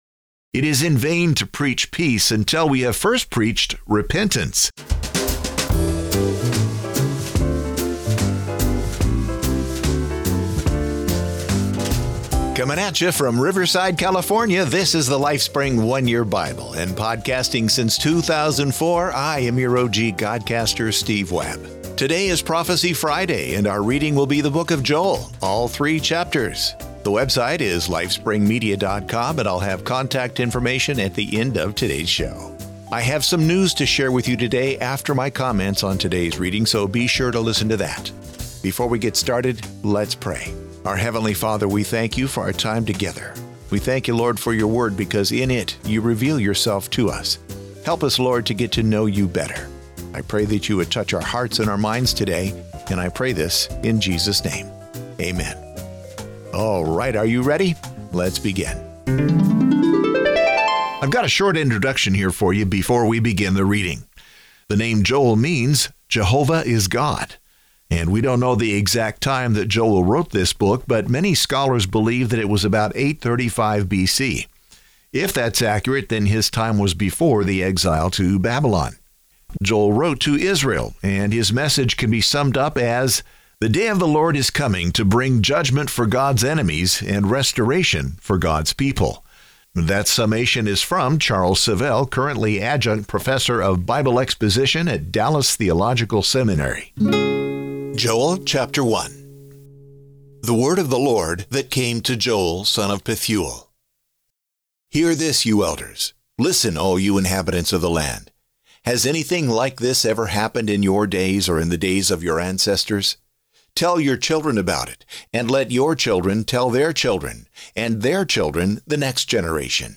We’ll read the book of Joel.